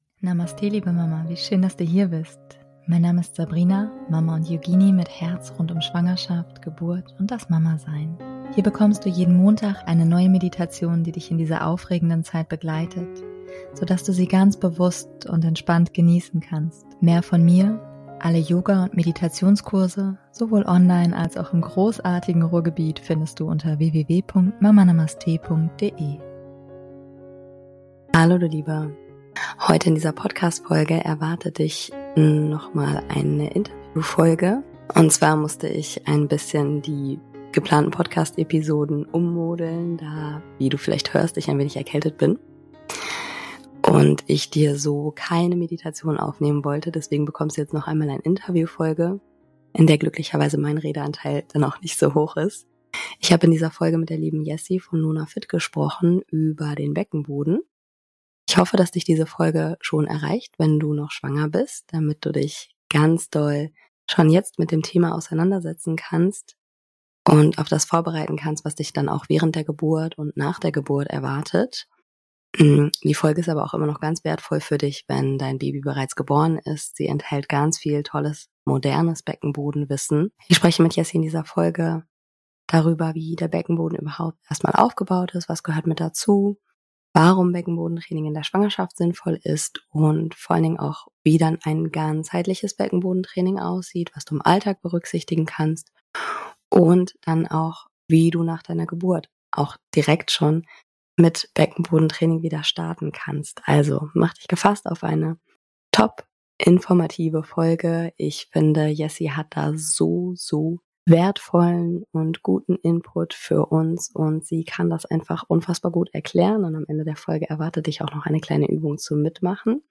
#183 - Wieso du dich in der Schwangerschaft mit deinem Beckenboden beschäftigen solltest - Interview